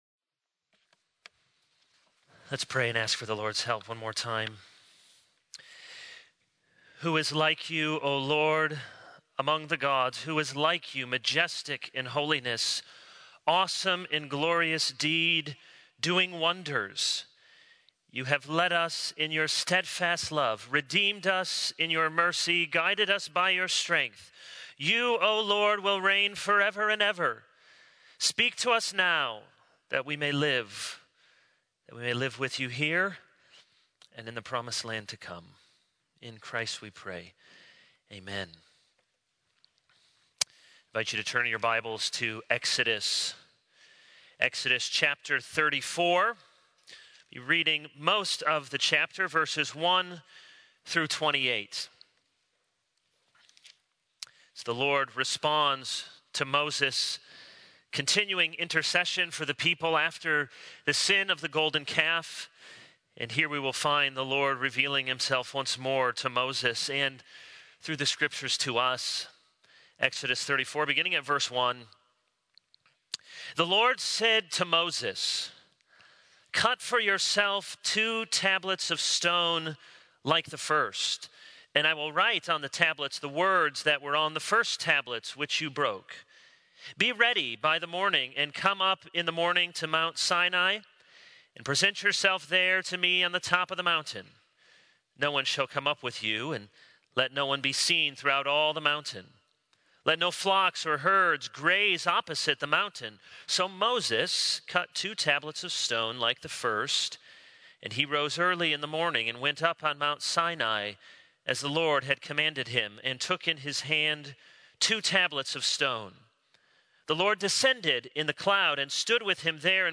This is a sermon on Exodus 34:1-28.